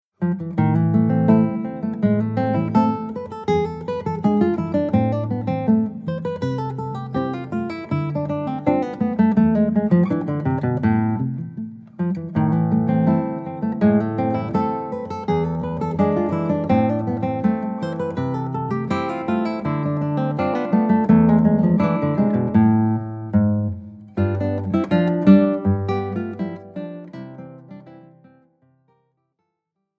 Sechs Jahrhunderte Gitarrenmusik für Gitarrenduo
Besetzung: 2 Gitarren
ROMANTIK